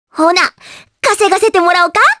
Requina-vox-select_jp.wav